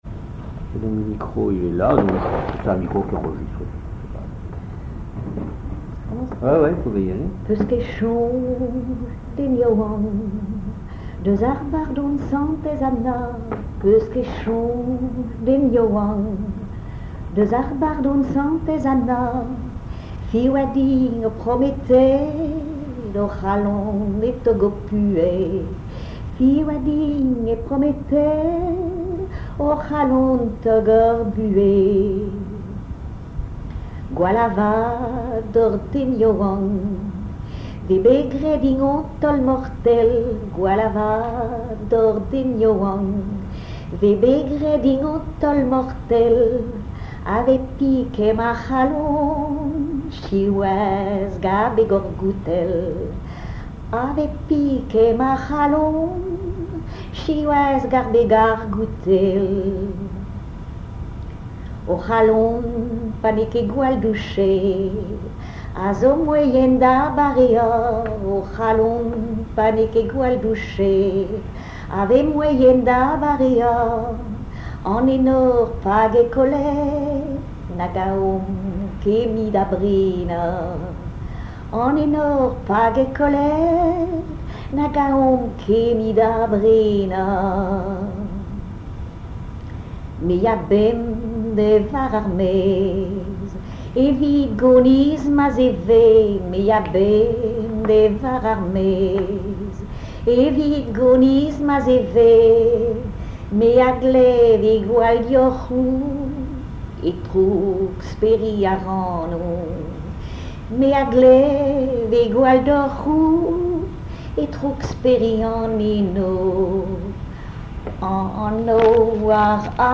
circonstance : dévotion, religion
Genre strophique
Chansons en breton
Pièce musicale inédite